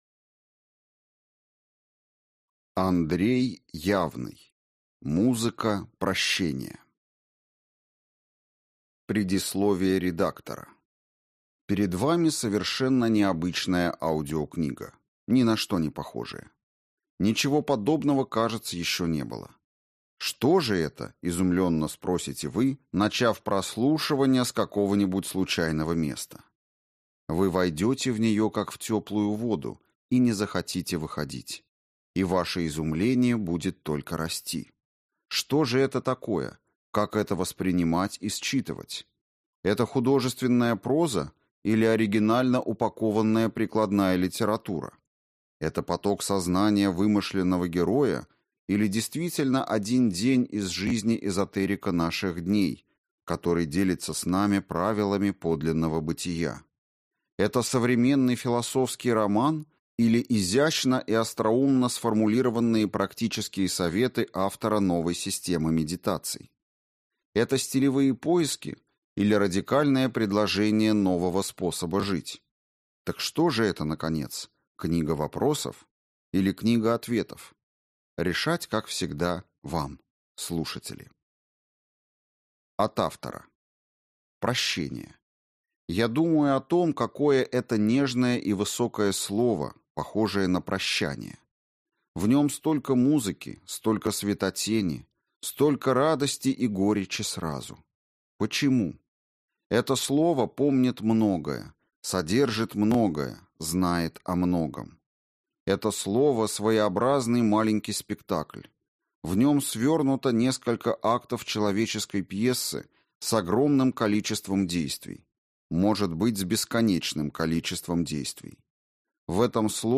Аудиокнига Музыка прощения | Библиотека аудиокниг
Прослушать и бесплатно скачать фрагмент аудиокниги